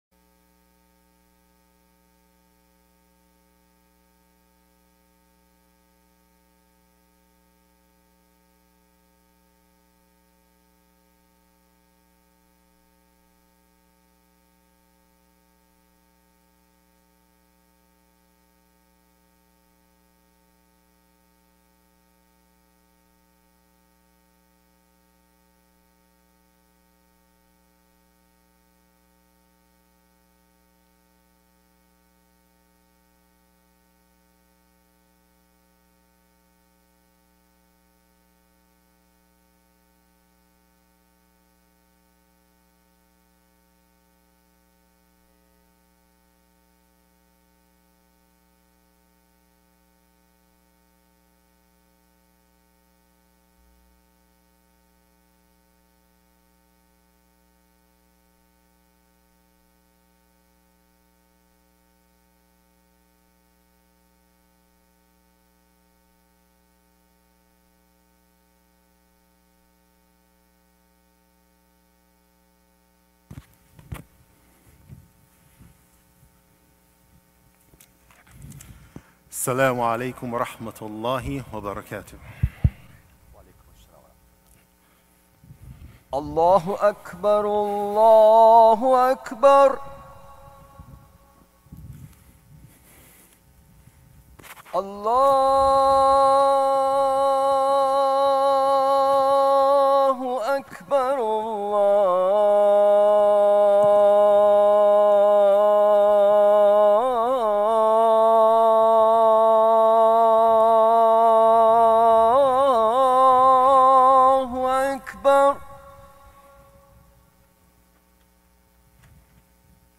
Friday Khutbah - "Sacred Justice"